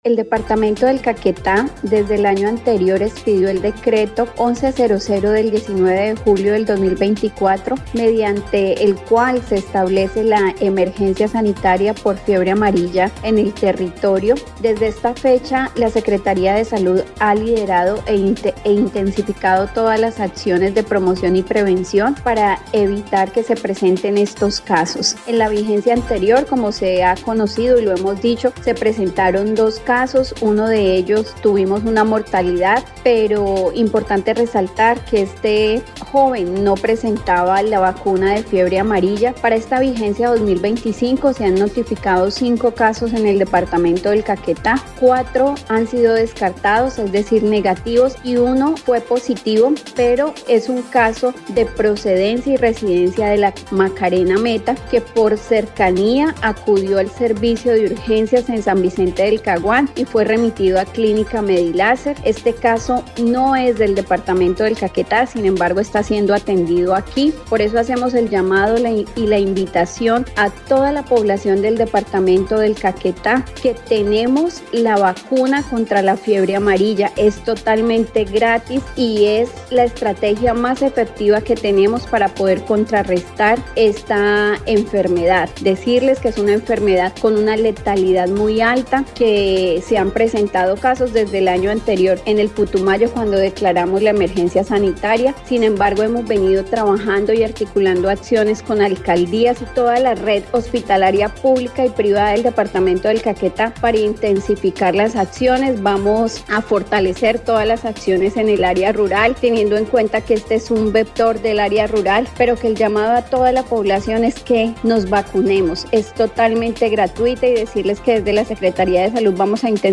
Así lo dio a conocer la secretaria de salud departamental, Mallerly Gonzales Arias, quien dijo que junto a la red pública hospitalaria se intensificará el proceso de vacunación contra la fiebre amarilla, especialmente en zonas rurales de cada municipio.